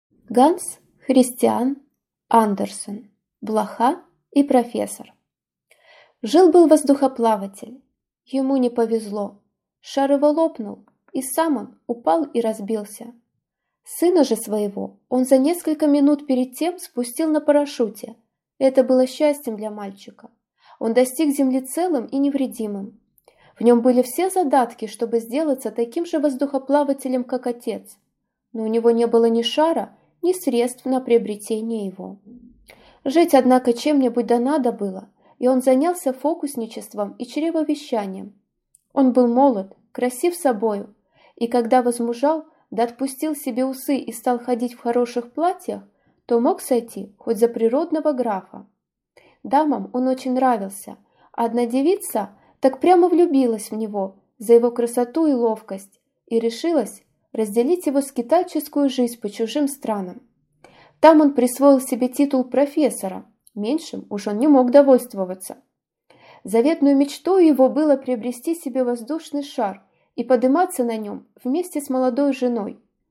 Аудиокнига Блоха и профессор | Библиотека аудиокниг